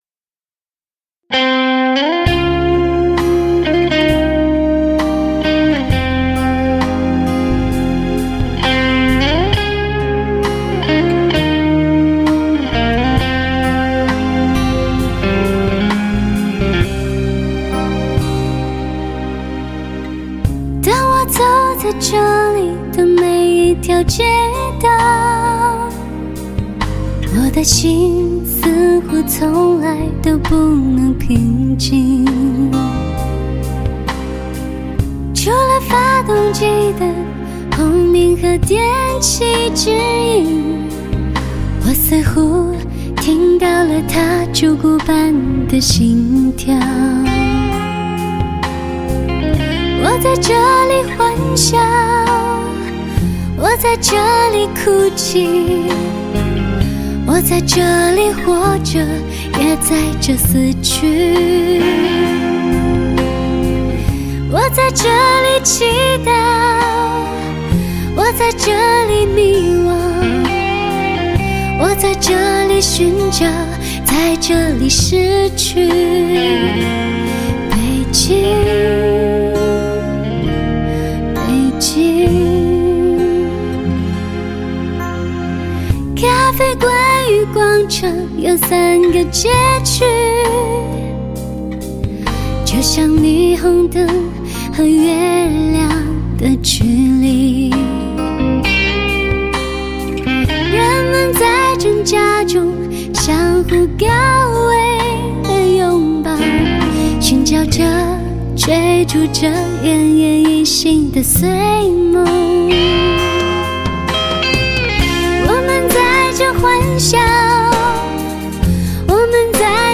重点突出优美的人声，最时尚的发烧编配，延续停不了精彩。